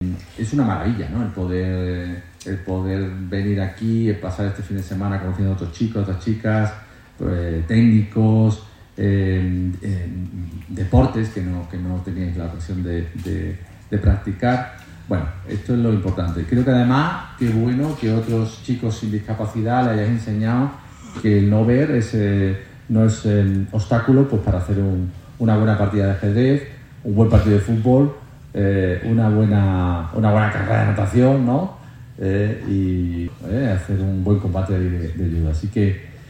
dijo en la clausura del encuentro. formato MP3 audio(0,69 MB)